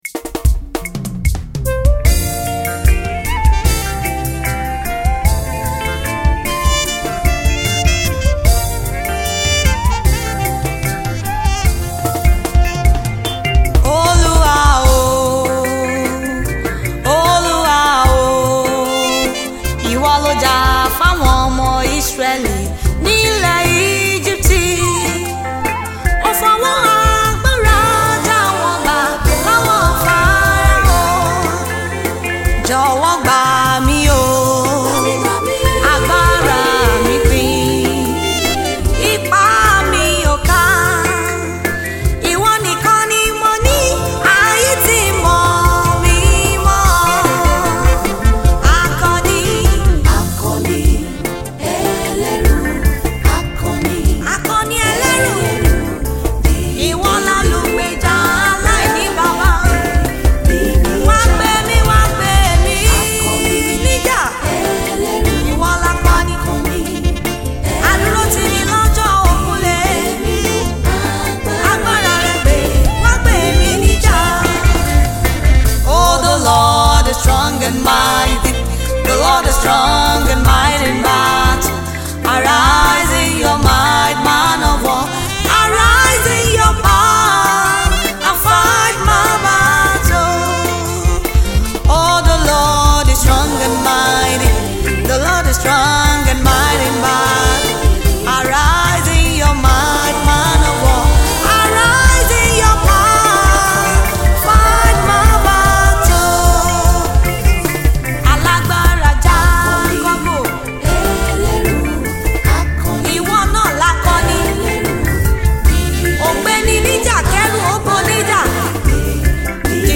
gospel
powerful and spirit filled single